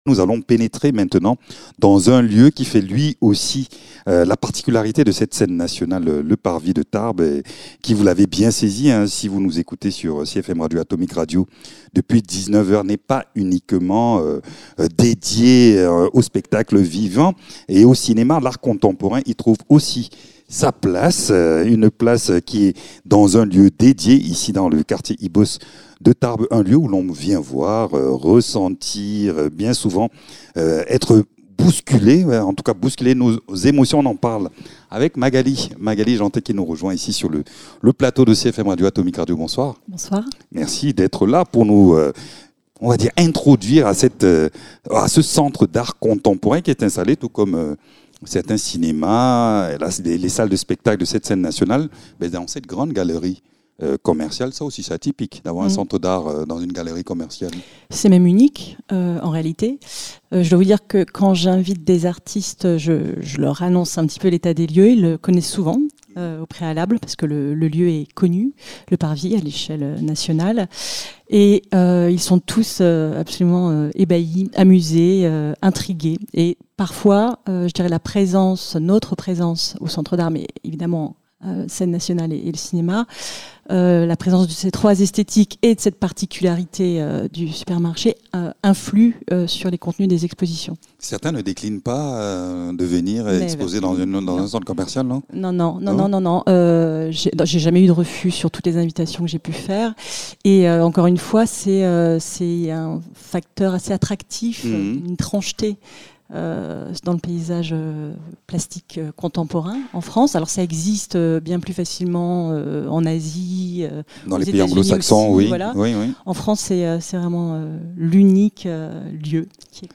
À travers expositions, résidences et actions de médiation, il accompagne les artistes et ouvre le regard des publics sur la création actuelle. Dans cet entretien, nous plongeons au cœur de ses missions, de ses choix curatoriaux et de sa place essentielle dans le paysage culturel des Hautes-Pyrénées.